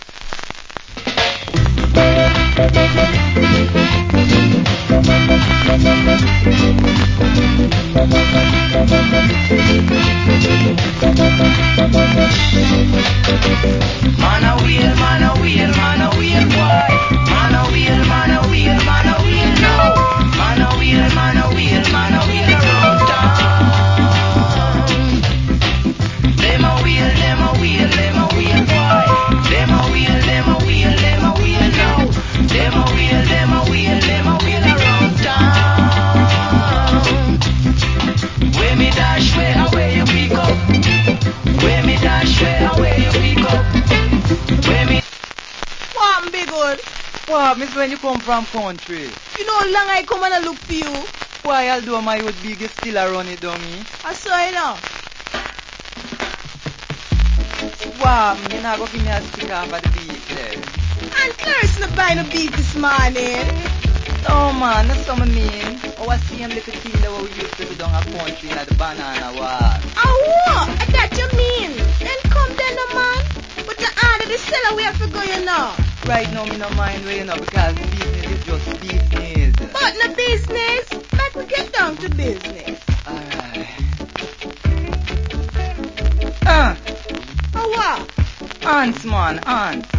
Rare Early Reggae.